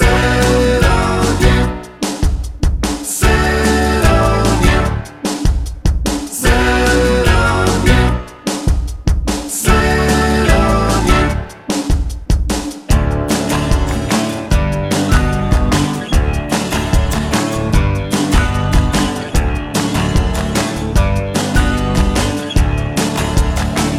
Two Semitones Down Pop (1970s) 3:51 Buy £1.50